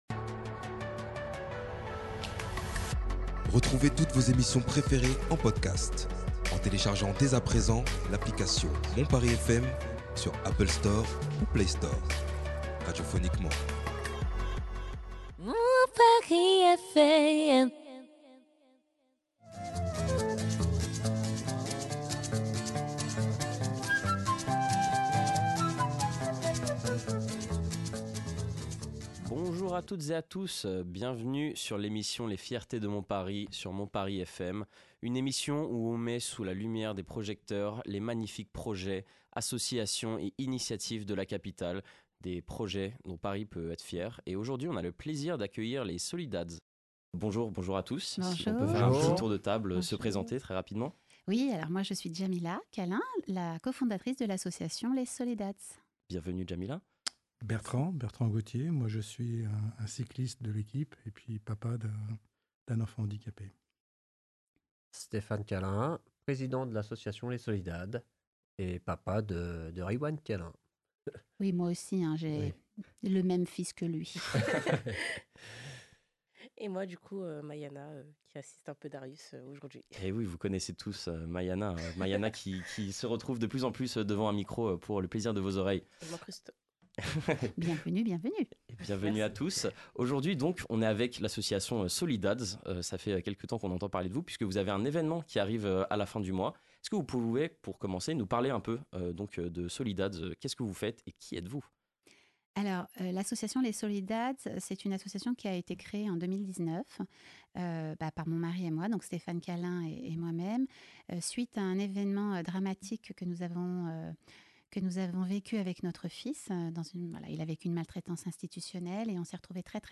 Dans cet épisode on reçoit l'association Solidads, des parents engagés pour leurs enfants atteints de maladies rares.